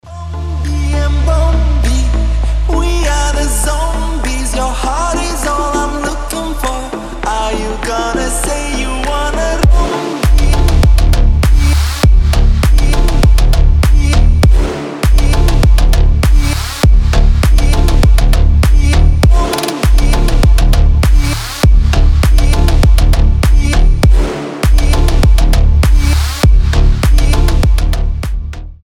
• Качество: 320, Stereo
мощные басы
Midtempo
Bass House
качающие